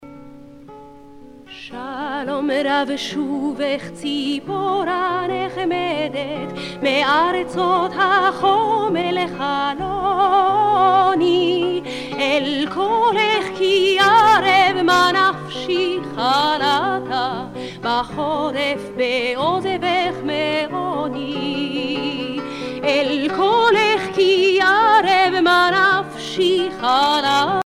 Cantilations bibliques